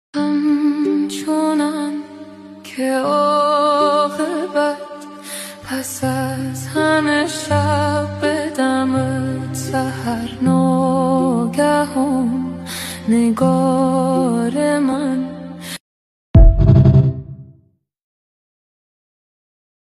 🎤 خواننده : صدای زن